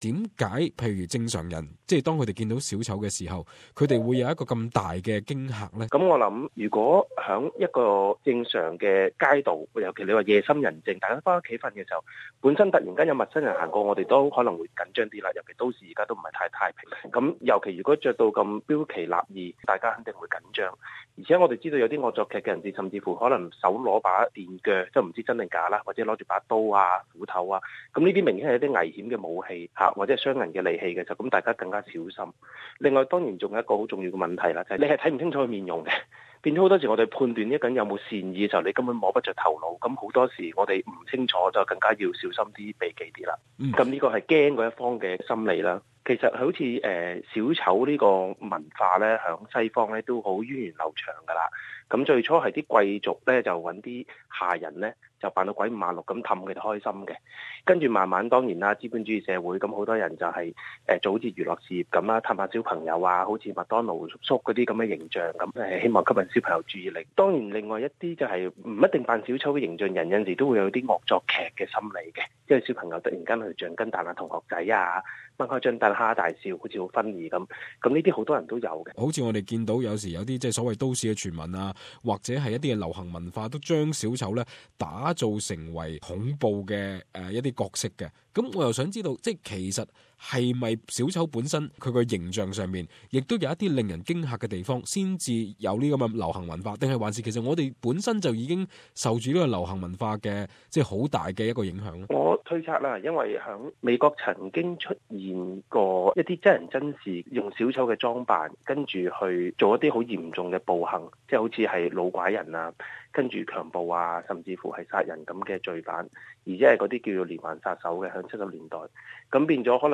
【時事專訪】澳洲驚現扮小丑整盅惡作劇